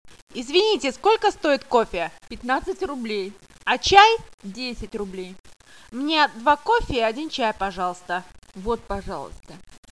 Kuuntele keskustelu ja vastaa kysymyksiin
kahvilassa2.mp3